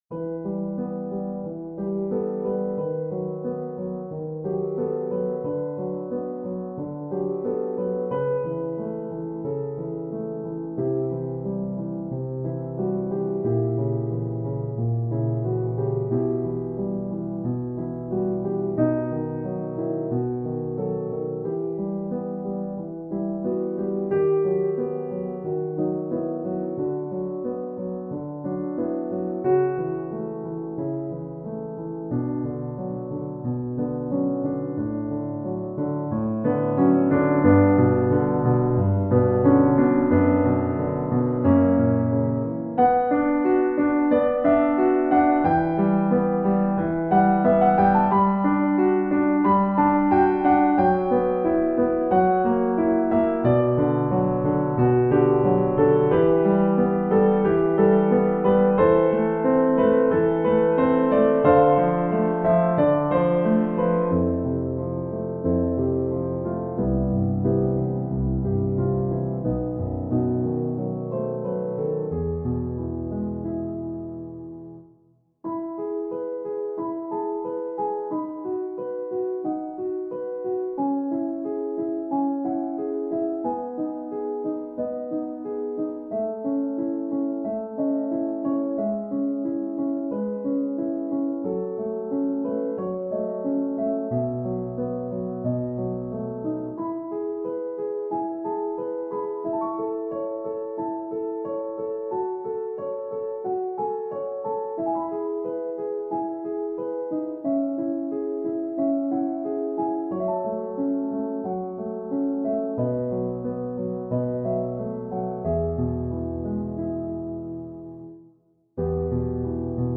van andere composities is er alleen een synthetische "weergave" (gemaakt met StaffPad).
Op.21 No.1 Intermezzo Piano solo StaffPad